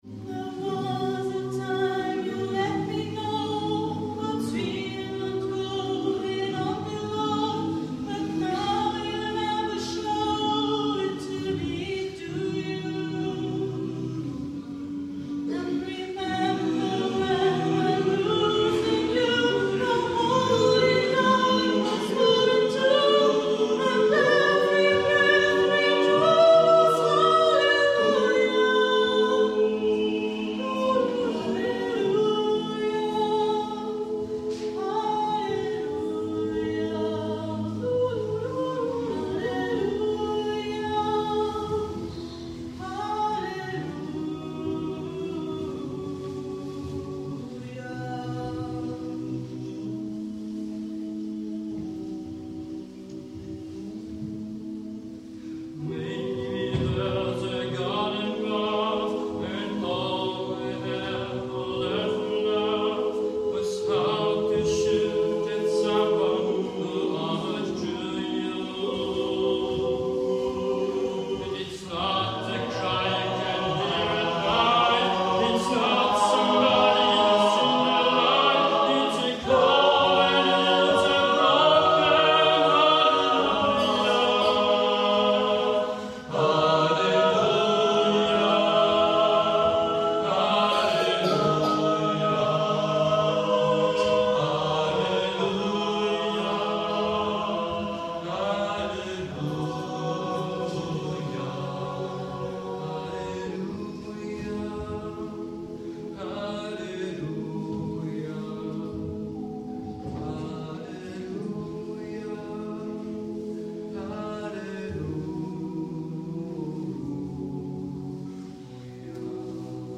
Pop/Rock/Gospel/Jazz